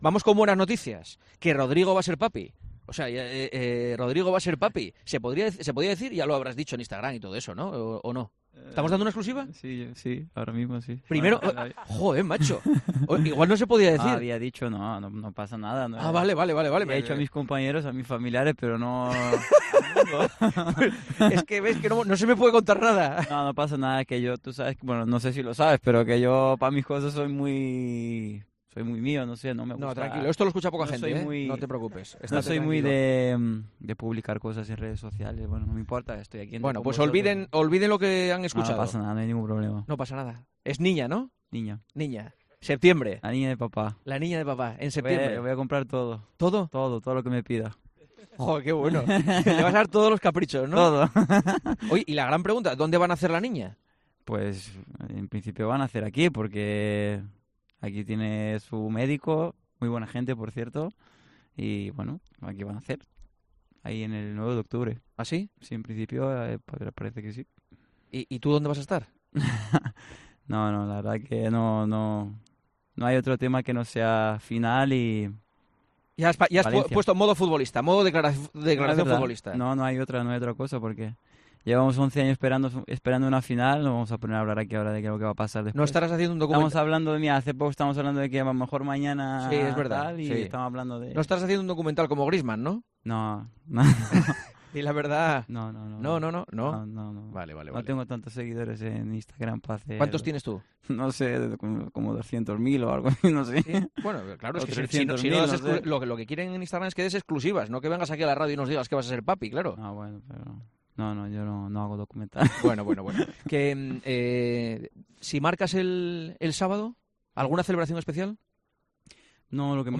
Juanma Castaño ha dirigido el programa de este martes desde Mestalla, a sólo cuatro días de la final que enfrentará a Valencia y Barcelona.